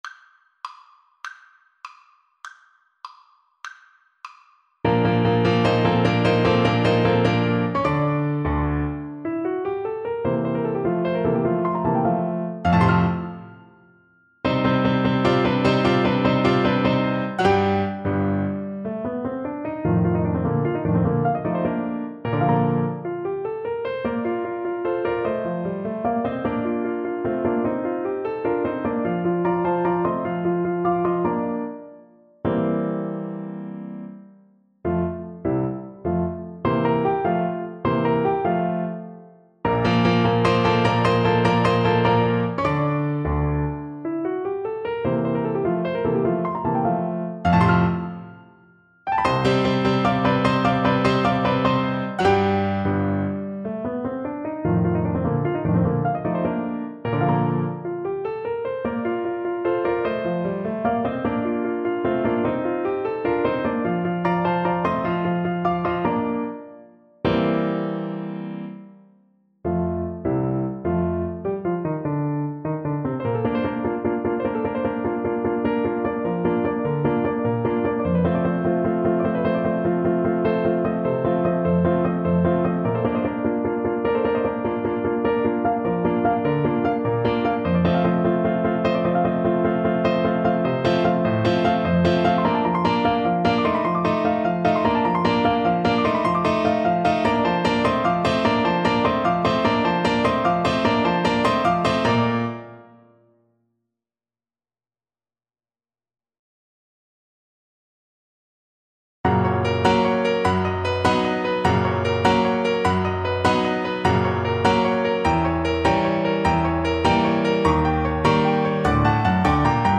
Classical Rossini, Giacchino Largo al factotum della citta (Barber of Seville) Clarinet version
Play (or use space bar on your keyboard) Pause Music Playalong - Piano Accompaniment Playalong Band Accompaniment not yet available transpose reset tempo print settings full screen
6/8 (View more 6/8 Music)
Allegro vivace . = c. 100 (View more music marked Allegro)
Classical (View more Classical Clarinet Music)